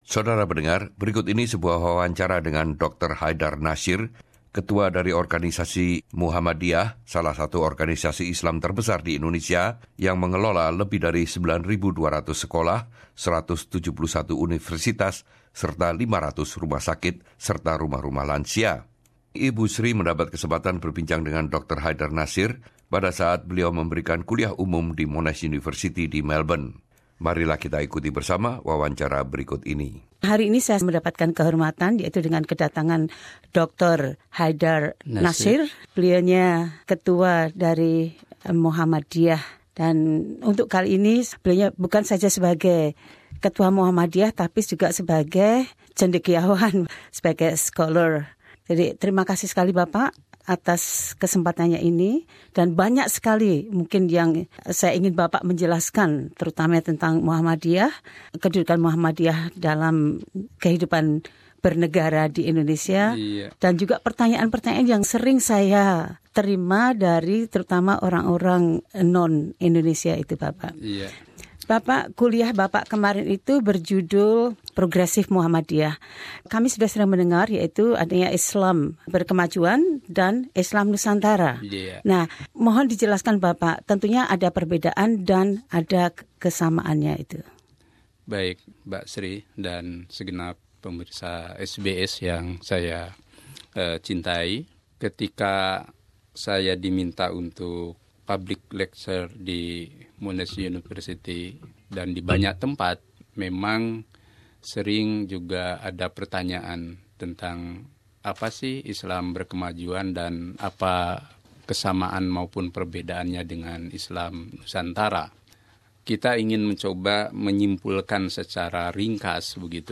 Dr Haedar Nashir in the SBS studio Melbourne, 17 Feb 2018.